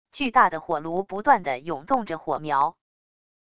巨大的火炉不断的涌动着火苗wav音频